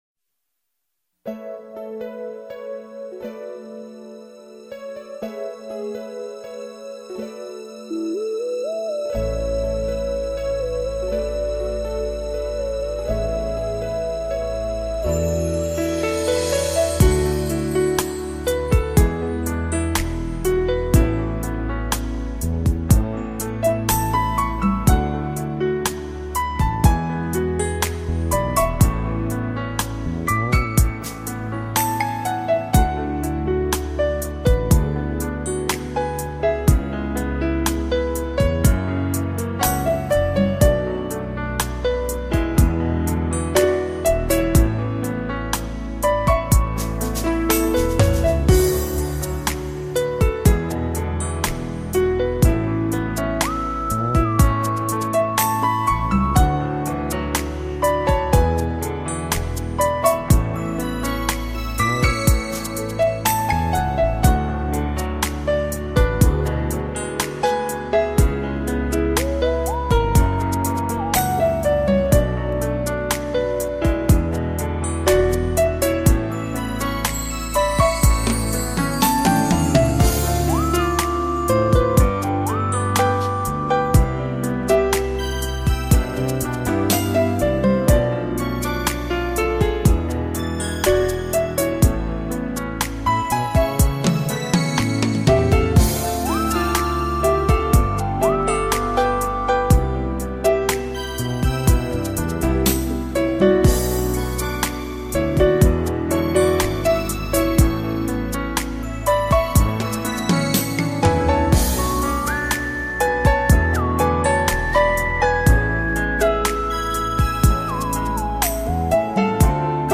【浪漫钢琴曲】《香草的天空·Vanilla Sky》 激动社区，陪你一起慢慢变老！